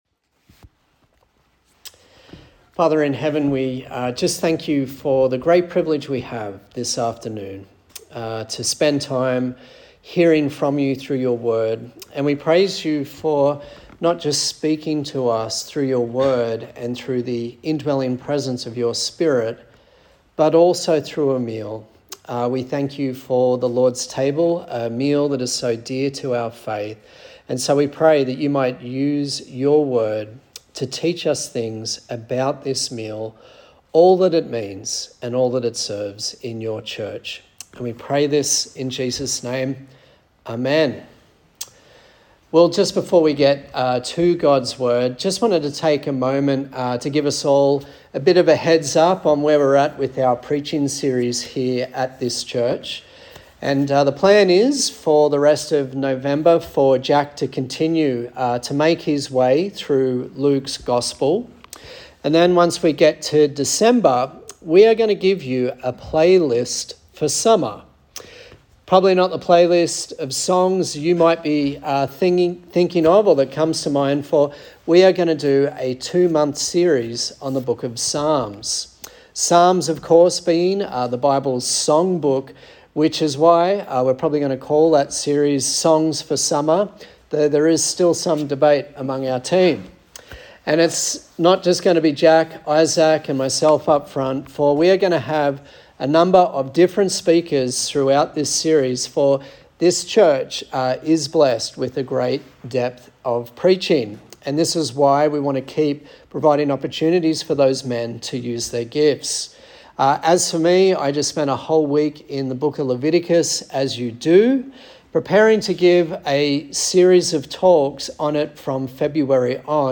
A sermon on the Lord's Supper
Service Type: Sunday Service